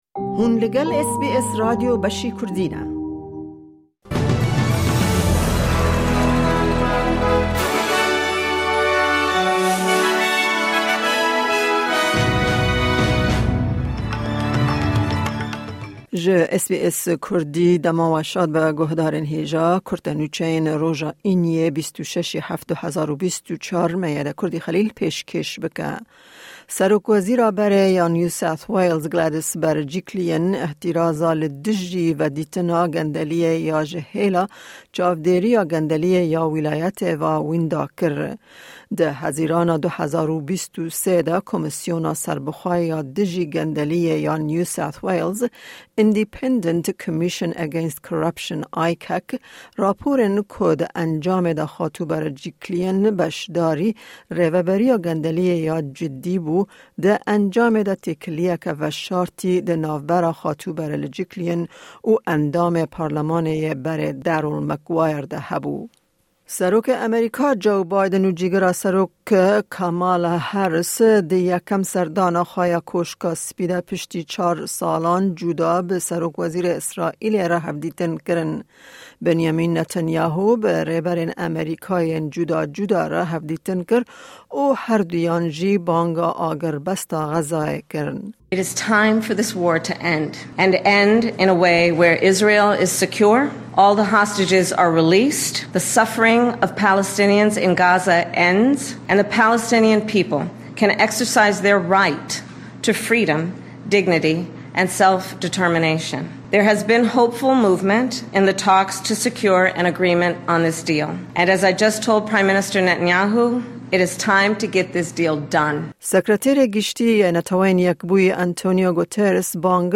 Kurte Nûçeyên roja Înî 26î tîrmeha 2024